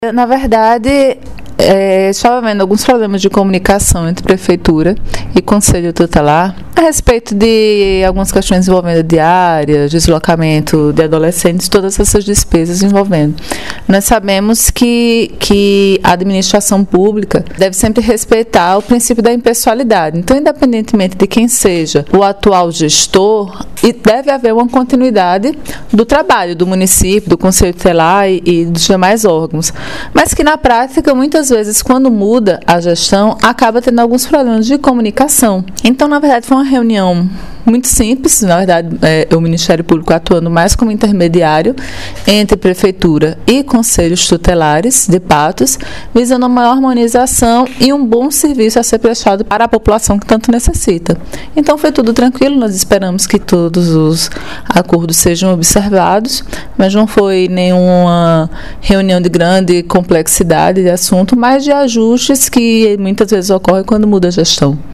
Fala da promotora da Vara da Infância e Juventude, Dra. Lívia Cabral –